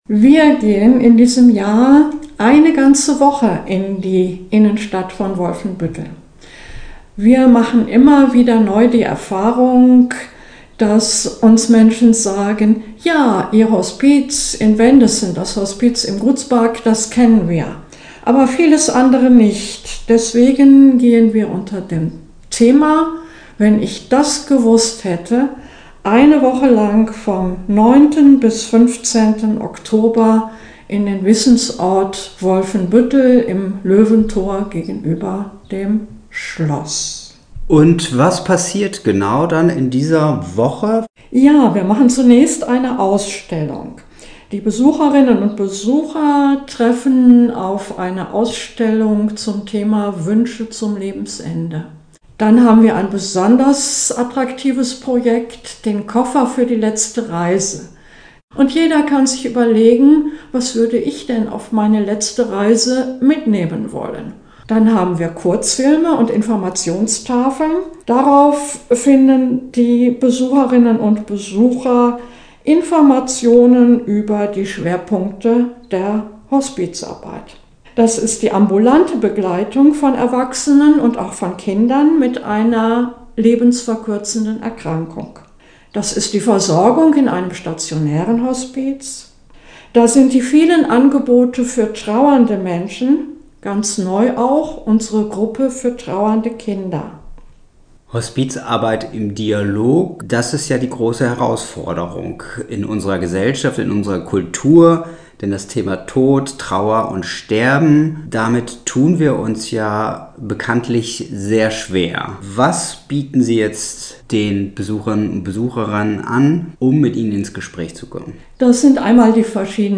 Interview-Hospizwoche-WF.mp3